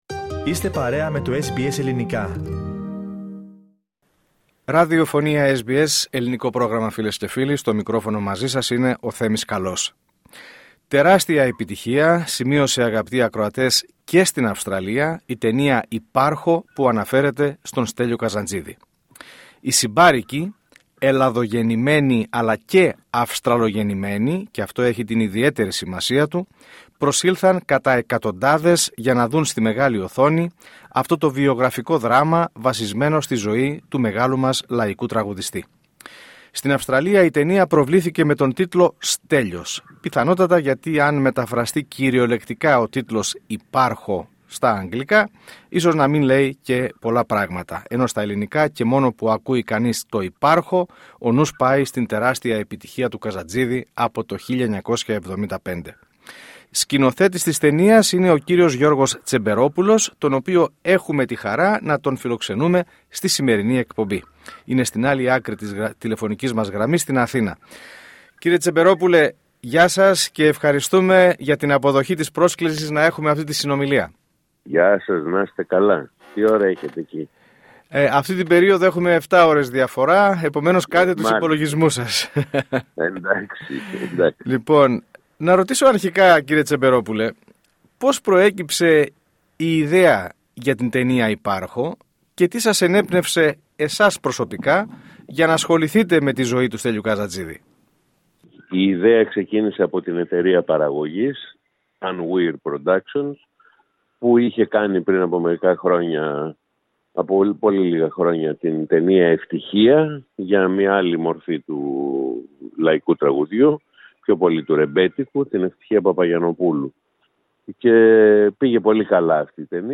Η ταινία «Υπάρχω», αφιερωμένη στον θρυλικό Στέλιο Καζαντζίδη, συνεχίζει την εντυπωσιακή της πορεία και στην Αυστραλία. Ο σκηνοθέτης Γιώργος Τσεμπερόπουλος, που βρέθηκε στο επίκεντρο της δημιουργίας, μίλησε στο Πρόγραμμά μας για την έμπνευση, τις προκλήσεις, αλλά και την ανταπόκριση του κοινού στην άλλη άκρη του κόσμου.